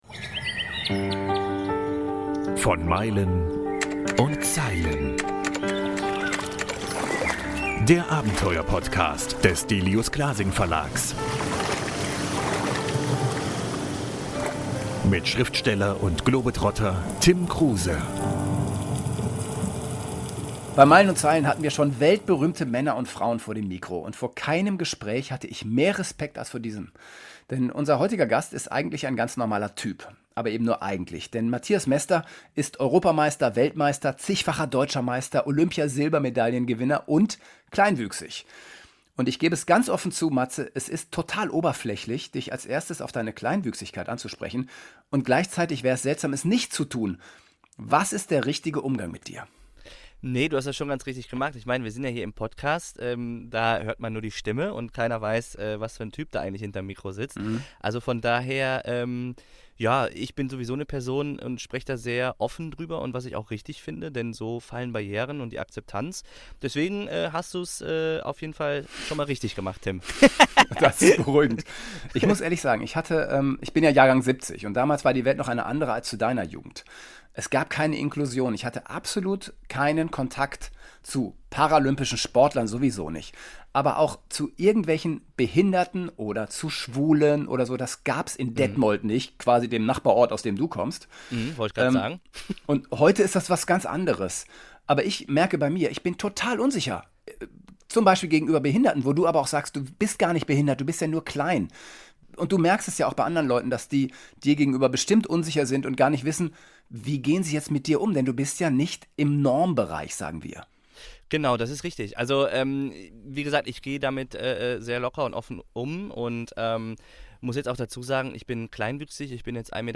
Es spricht heute im "Meilen und Zeilen"-Interview: der Welt-Mester! Der kleinwüchsige Leichtathlet Mathias Mester nimmt kein Blatt vor den Mund.